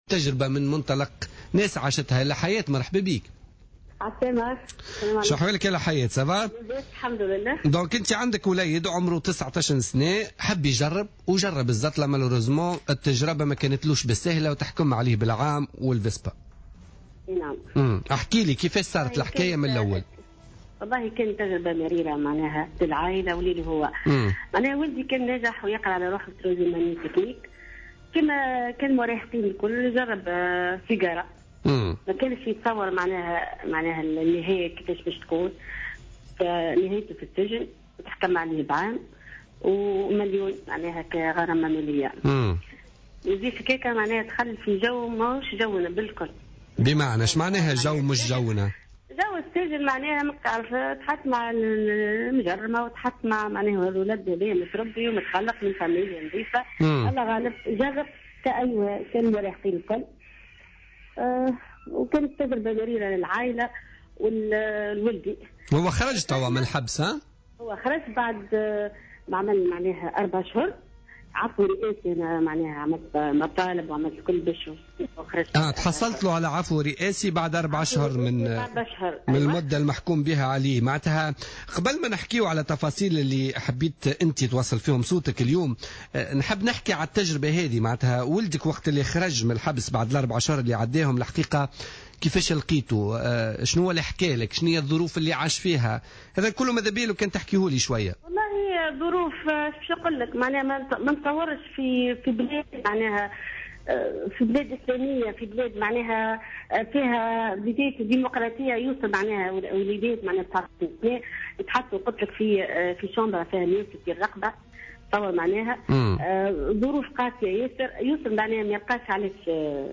أم تتحدث عن تجربة ابنها مع الزطلة لأول مرة